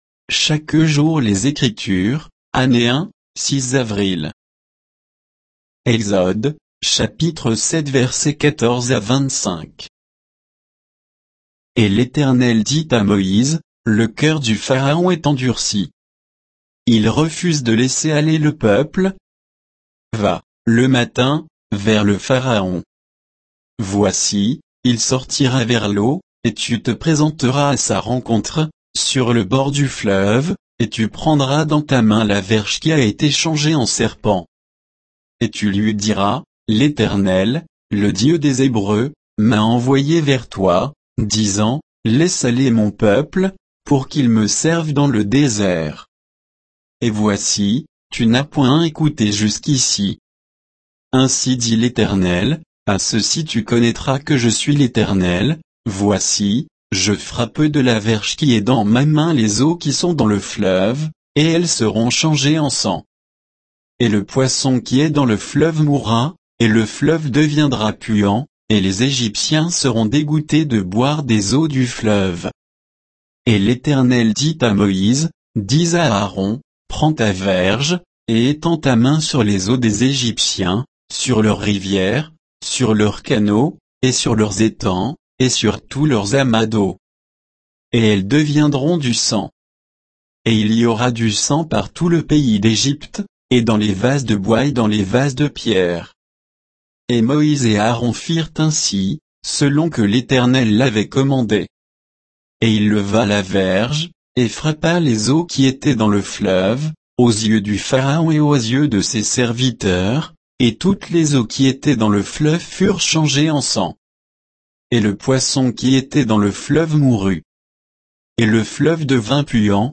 Méditation quoditienne de Chaque jour les Écritures sur Exode 7, 14 à 25